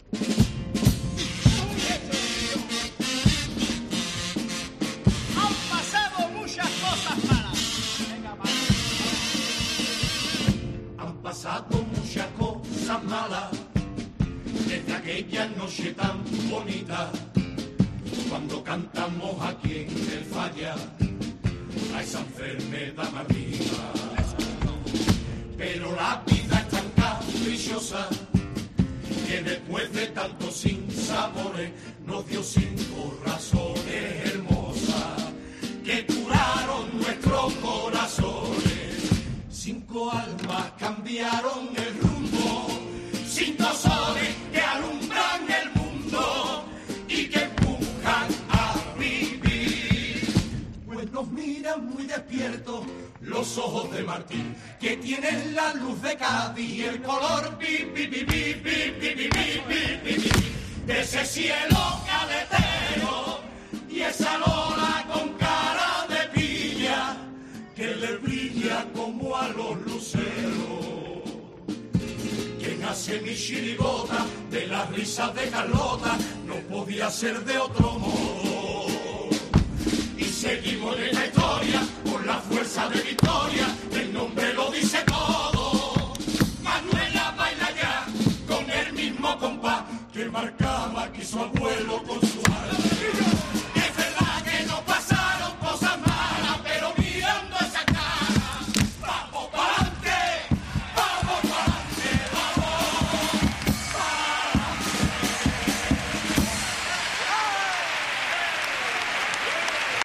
El segundo pasodoble de La misión, el evangelio según Santander